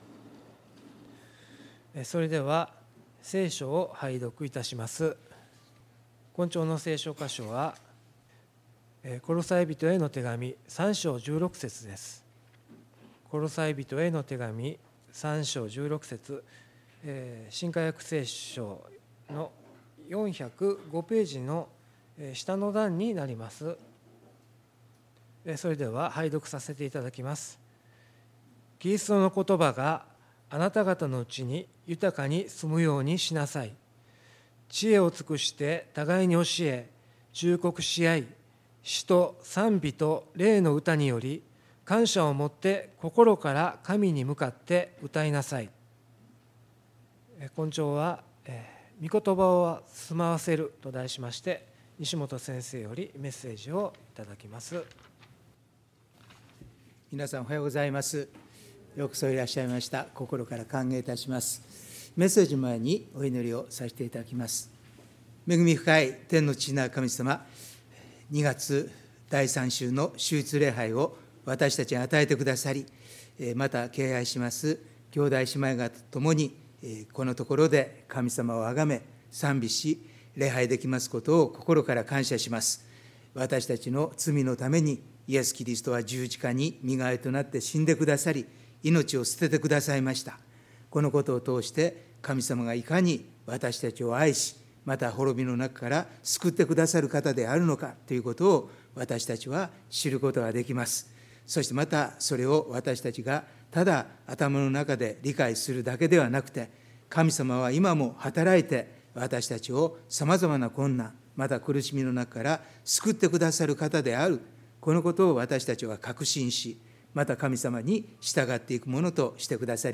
礼拝メッセージ「御言を住まわせる」│日本イエス・キリスト教団 柏 原 教 会